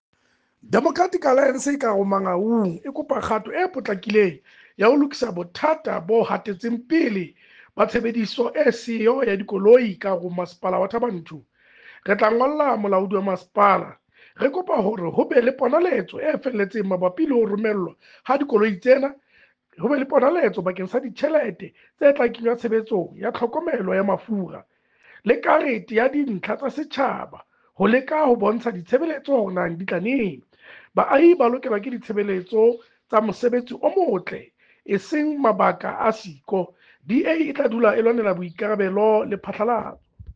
Sesotho soundbites by Cllr Kabelo Moreeng.